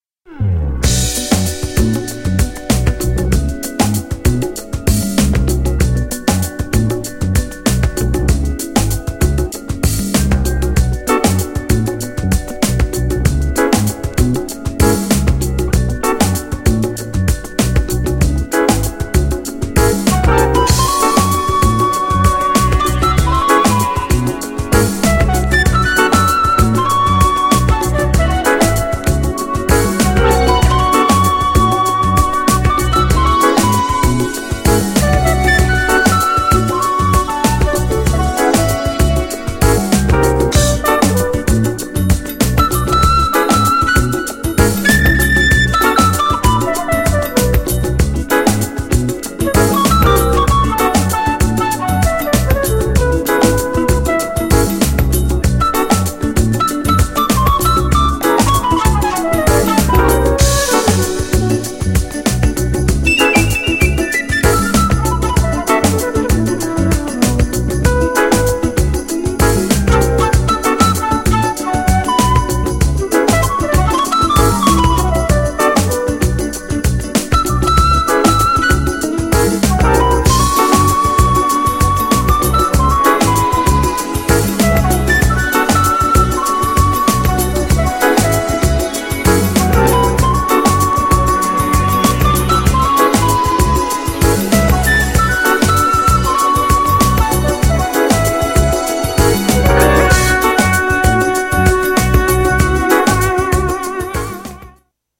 FLUTEをフィーチャーしたインストも最高にステキ!!
GENRE R&B
BPM 96〜100BPM